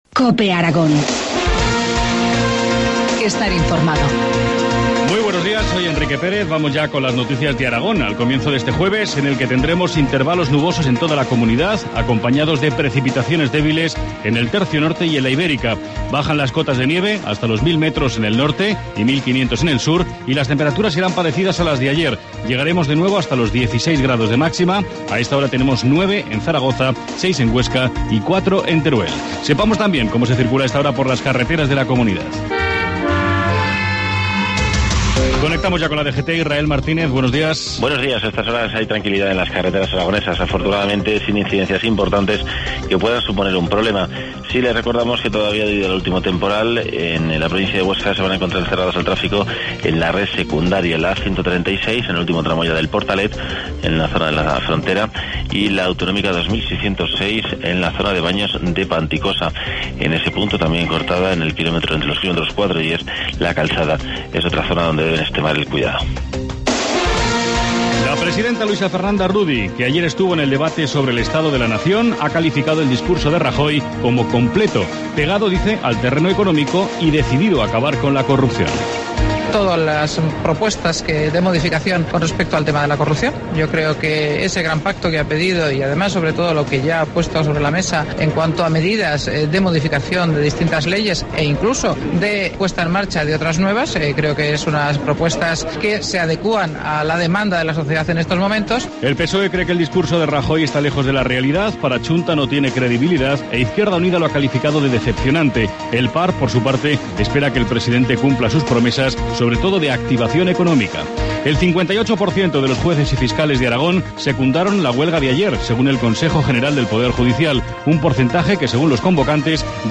Informativo matinal, jueves 21 de febrero, 7.25 horas